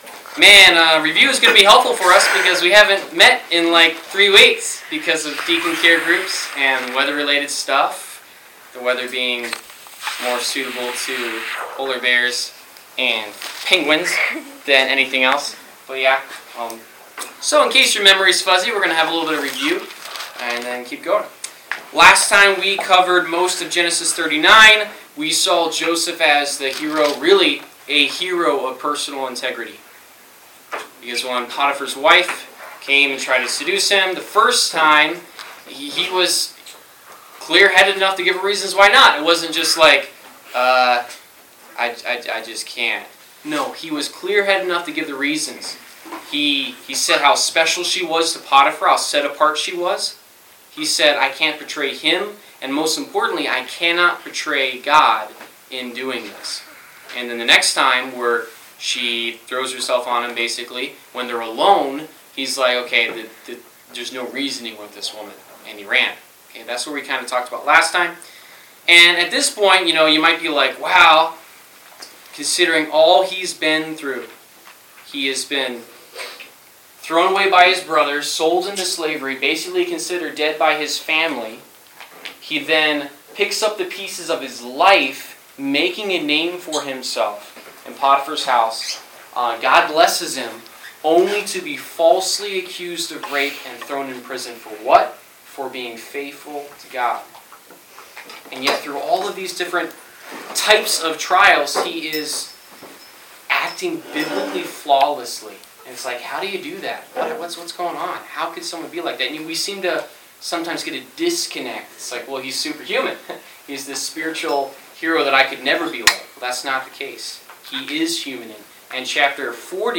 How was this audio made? Service Type: Wednesday Night - Youth Group Topics: Injustice , Penn Jillette , Suffering , The purpose of trials , Waiting on God